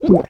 Minecraft Version Minecraft Version latest Latest Release | Latest Snapshot latest / assets / minecraft / sounds / entity / witch / drink3.ogg Compare With Compare With Latest Release | Latest Snapshot
drink3.ogg